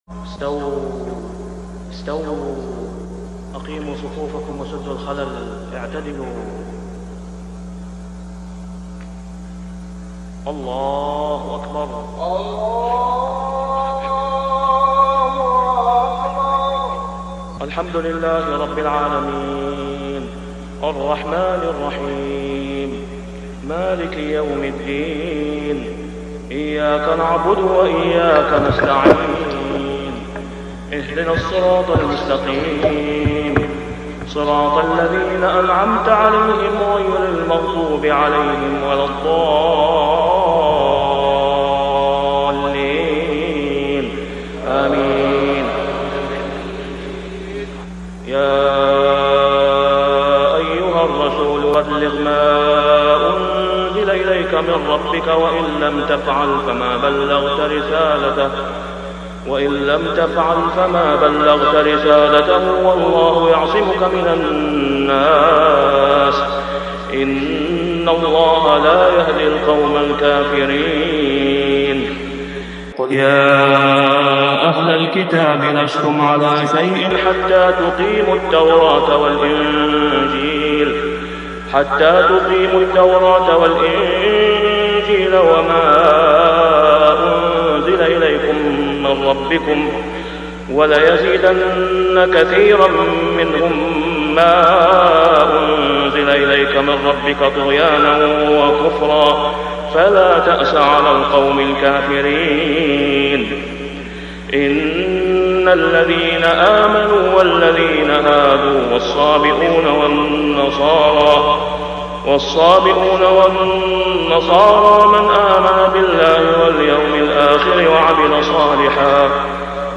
صلاة العشاء ( العام مجهول ) | سورة المائدة 67-75 | > 1423 🕋 > الفروض - تلاوات الحرمين